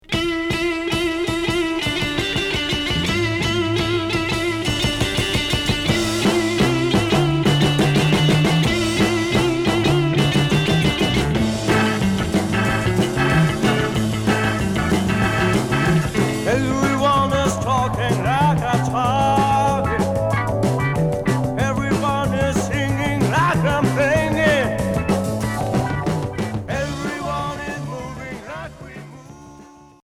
Pop Rock Premier 45t retour à l'accueil